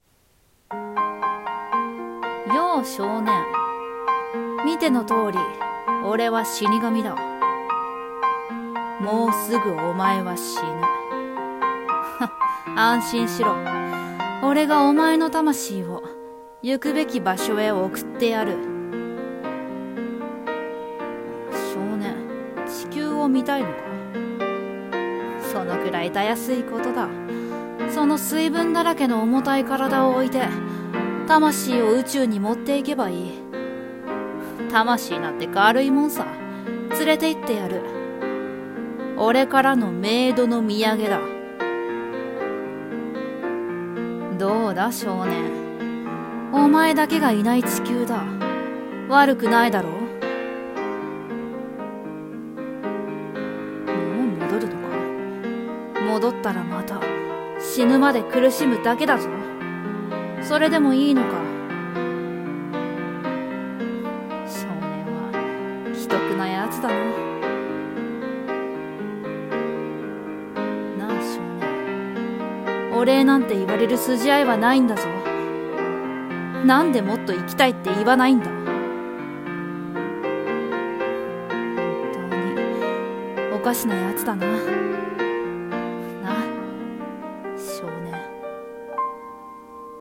声劇「少年と死神」